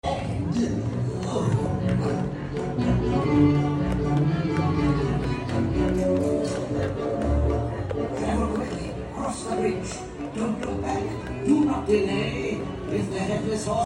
The Headless Horseman rides right at the beginning of the parade.